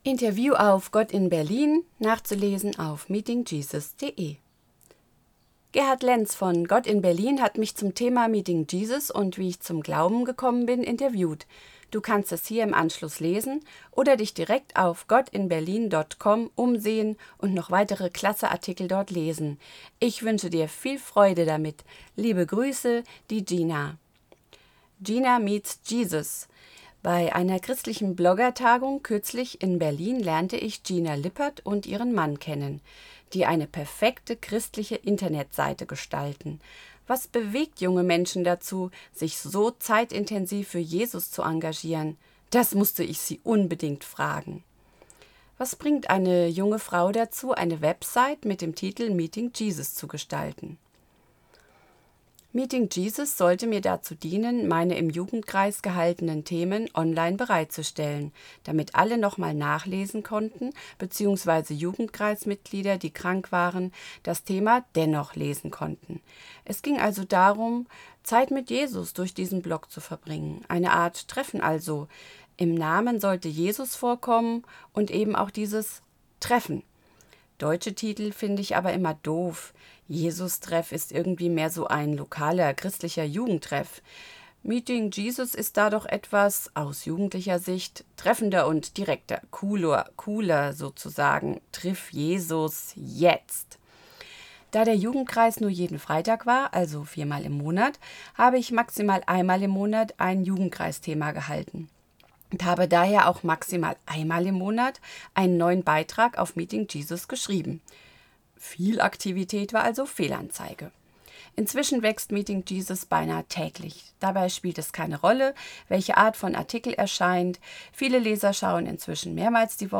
Interview auf Gott-in-Berlin